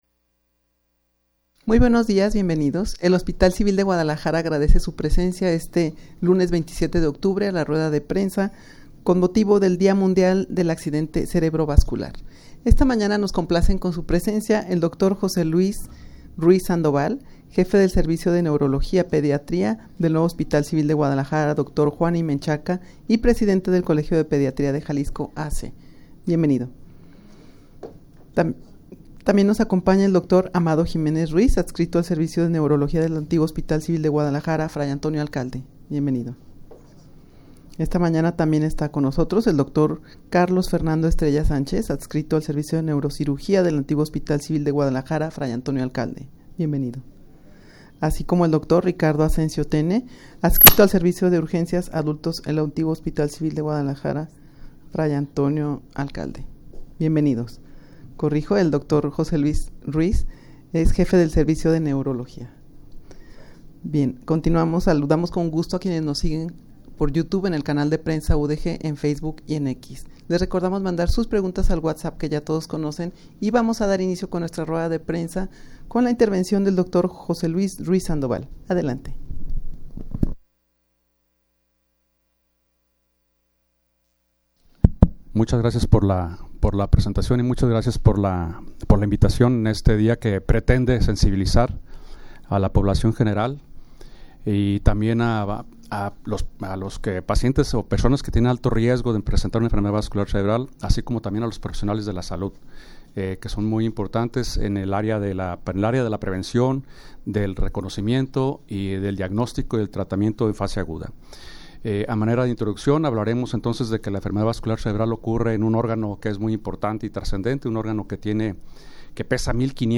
Audio de la Rueda de Prensa
rueda-de-prensa-con-motivo-del-dia-mundial-del-accidente-cerebrovascular.mp3